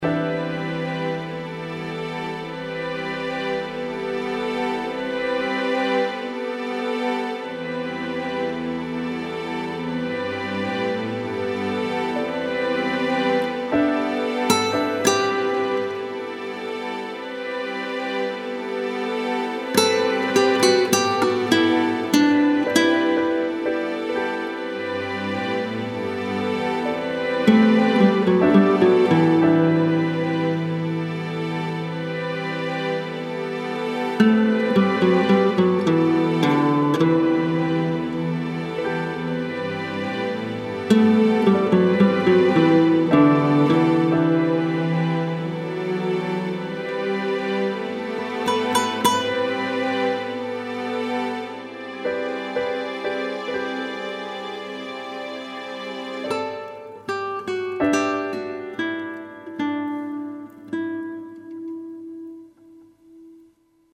Ambient, mysterious with guitar